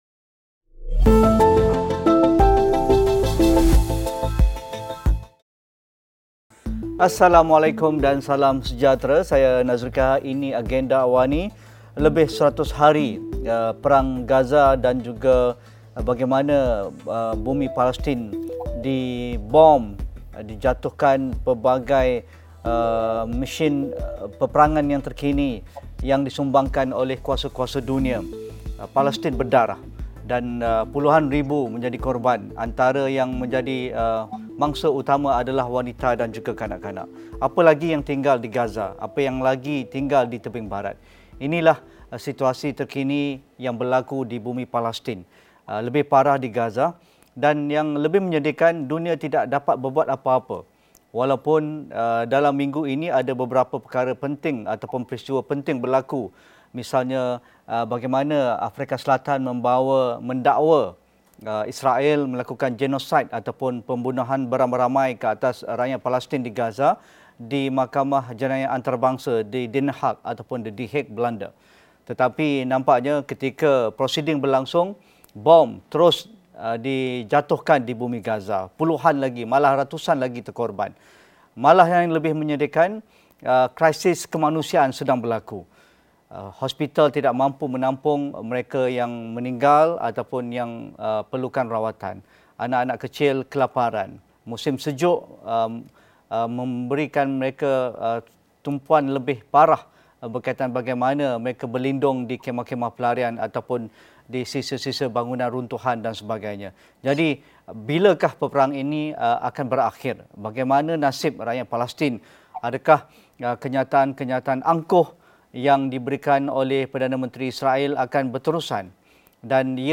Apa yang perlu kita fahami tentang krisis yang berlaku di laluan Laut Merah pada ketika ini? Sejauh mana ia beri tekanan kepada rejim Israel dan impaknya terhadap krisis geopolitik di rantau Timur Tengah itu? Diskusi 8.30 malam